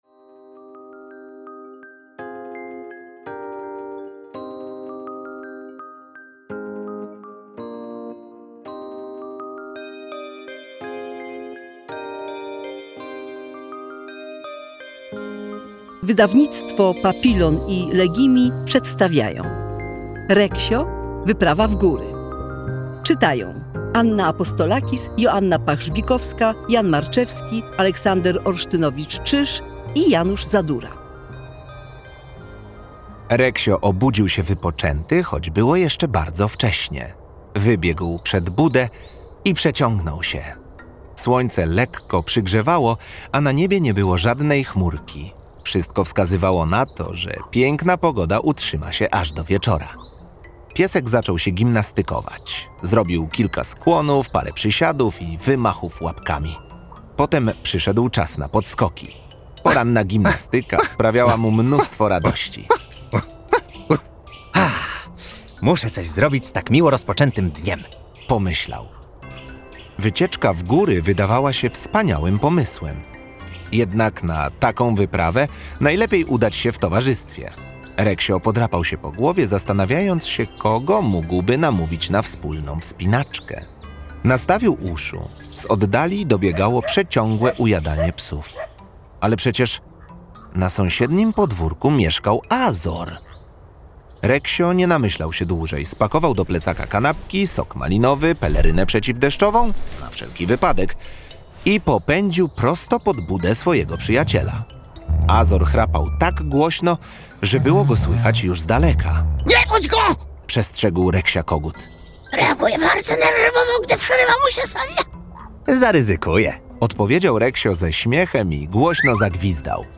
Reksio. Wyprawa w góry - Maria Szarf - audiobook